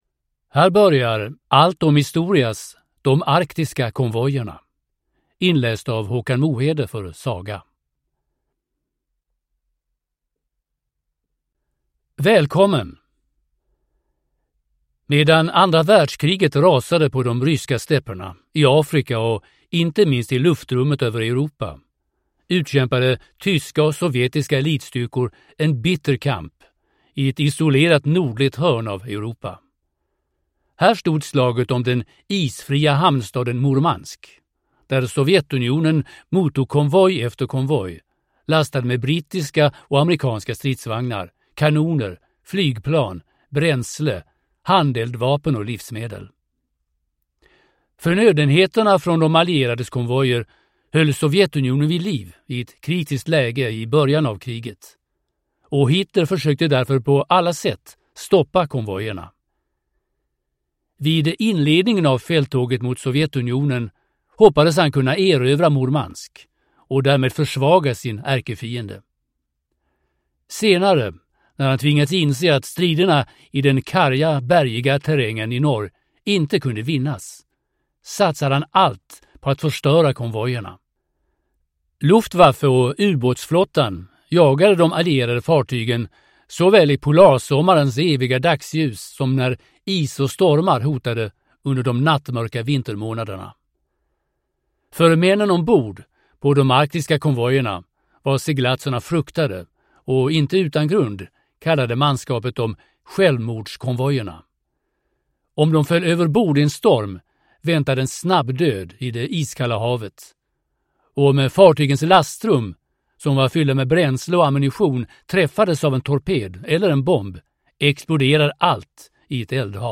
De arktiska konvojerna (ljudbok) av Allt om Historia